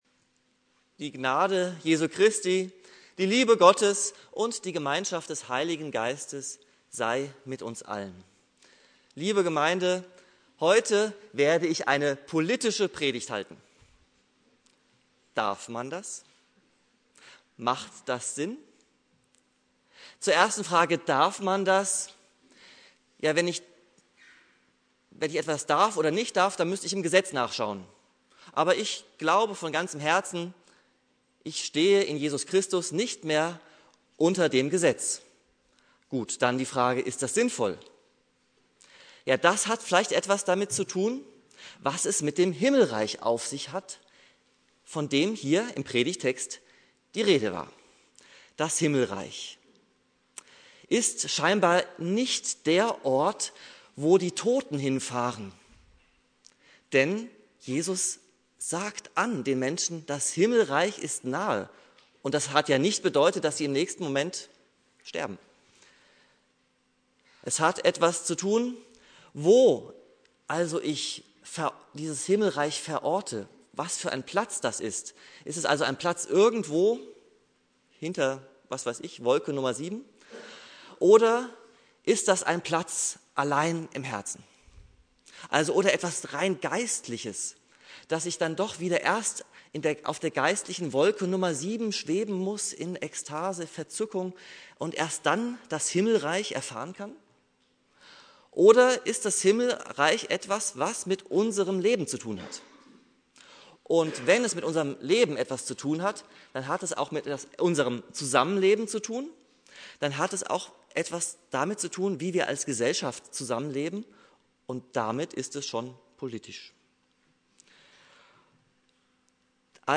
"Das Himmelreich Gottes" - Eine politische Predigt